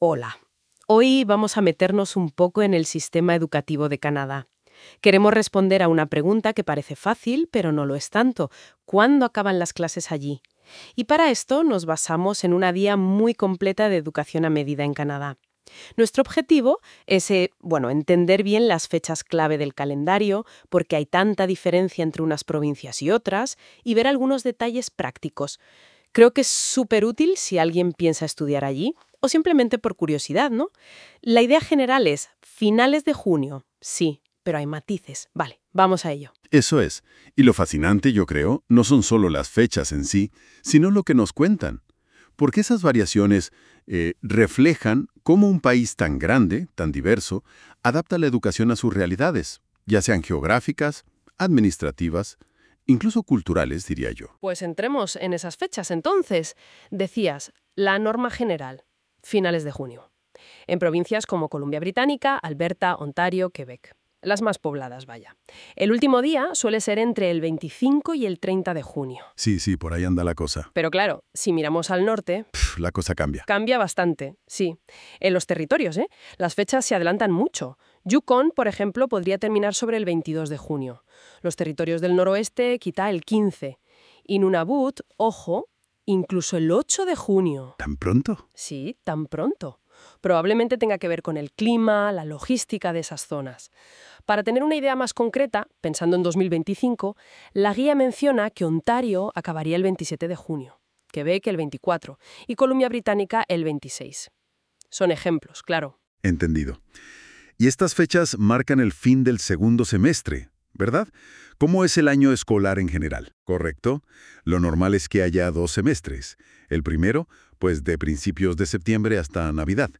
Te dejamos un podcast que trata sobre cuando termina el curso escolar en Canadá, por si lo quieres escuchar antes de leer el artículo.